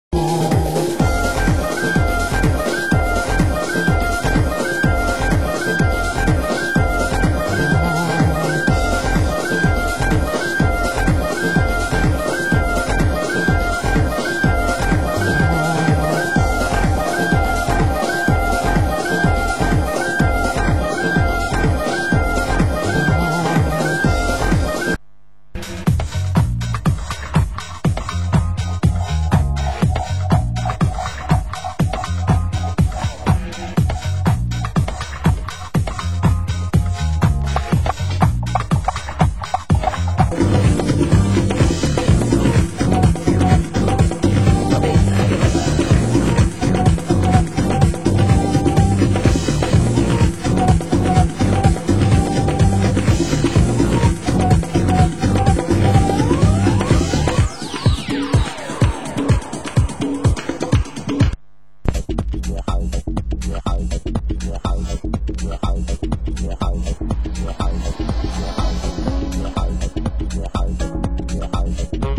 Genre UK House